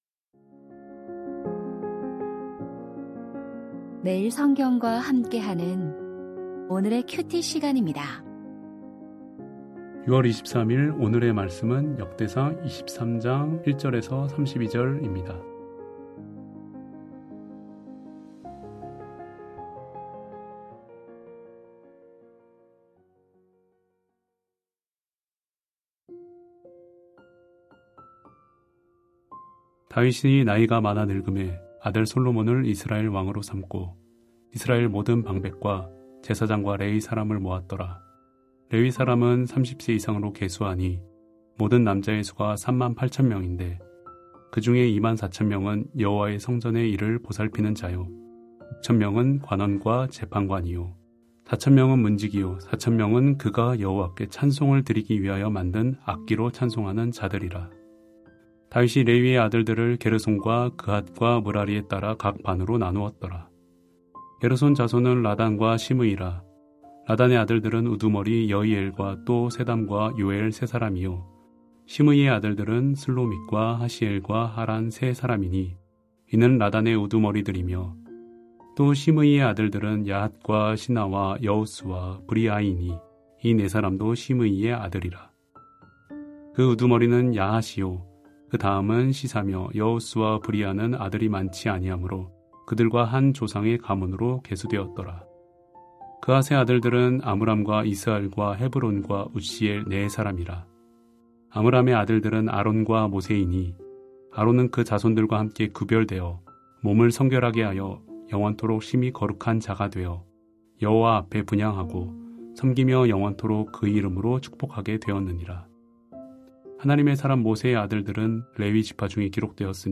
역대상 23:1-32 새 술은 새 부대에 2025-06-23 (월) > 오디오 새벽설교 말씀 (QT 말씀묵상) | 뉴비전교회